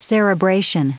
Prononciation du mot cerebration en anglais (fichier audio)
Prononciation du mot : cerebration